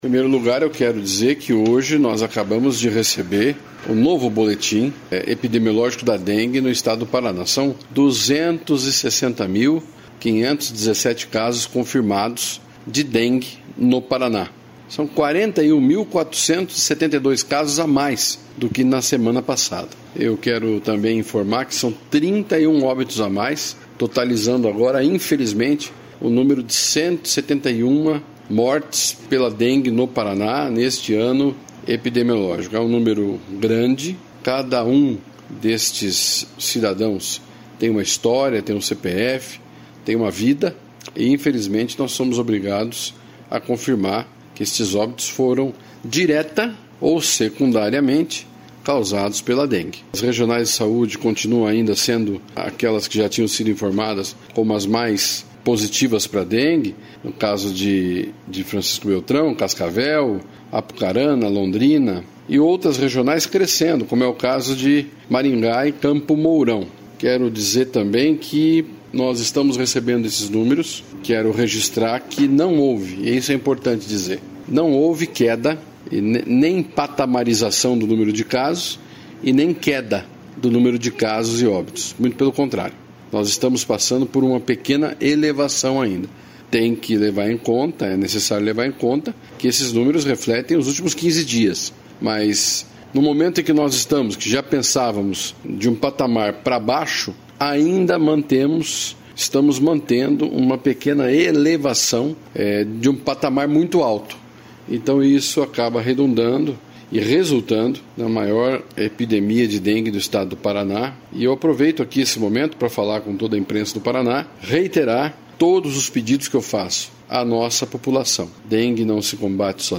Sonora do secretário da Saúde, Beto Preto, sobre o novo boletim epidemiológico da dengue